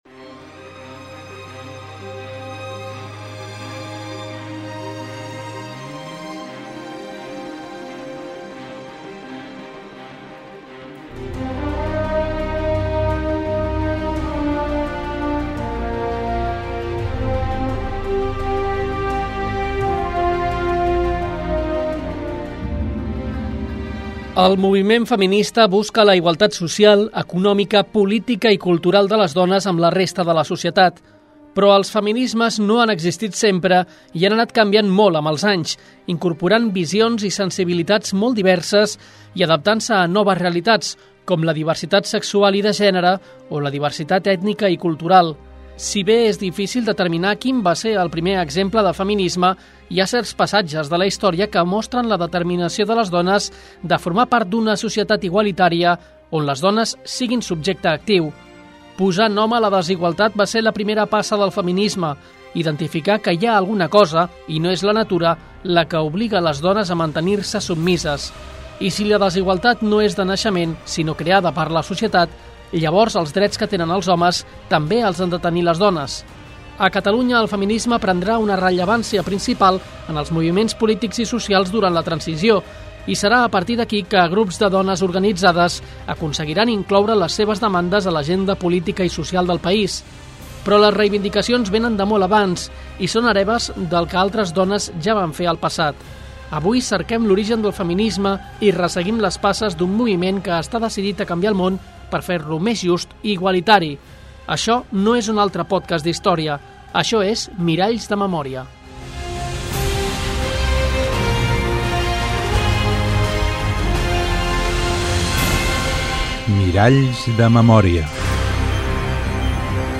Amb la col·laboració d'alumnat dels instituts Salvador Dalí, Estany de la Ricarda, Baldiri Guilera i Ribera Baixa i amb la teatralització del grup de joves del teatre Kaddish.